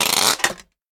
box_glove_retract_01.ogg